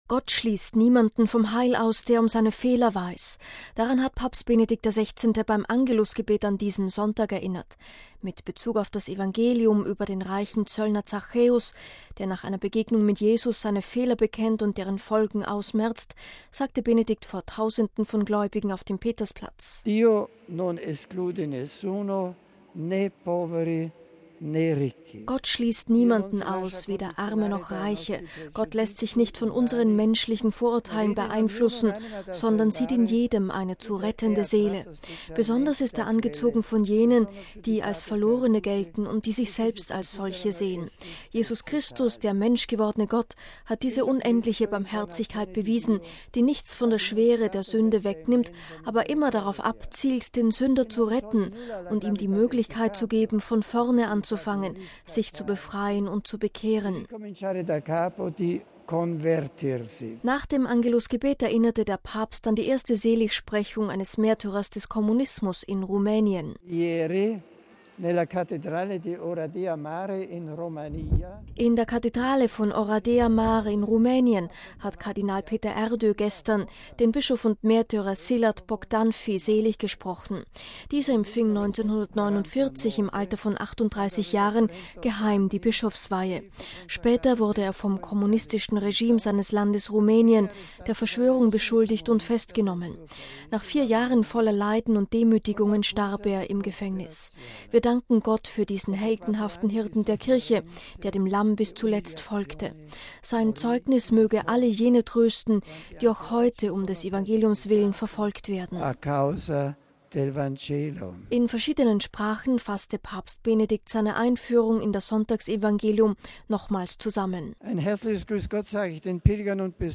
MP3 Gott schließt niemanden vom Heil aus, der um seine Fehler weiß. Daran hat Papst Benedikt XVI. beim Angelusgebet an diesem Sonntag erinnert.
In verschiedenen Sprachen fasste Papst Benedikt seine Einführung in das Sonntagsevangelium nochmals zusammen.